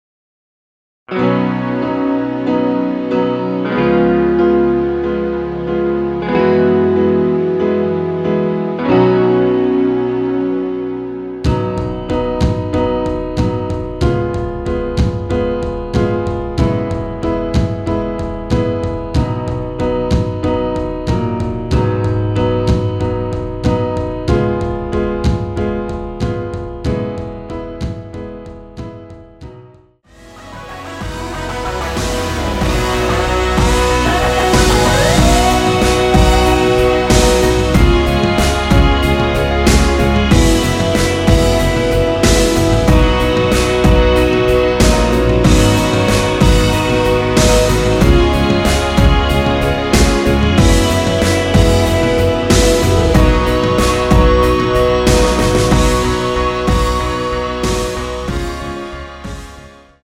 원키에서(-2)내린 MR입니다.
앞부분30초, 뒷부분30초씩 편집해서 올려 드리고 있습니다.
중간에 음이 끈어지고 다시 나오는 이유는
곡명 옆 (-1)은 반음 내림, (+1)은 반음 올림 입니다.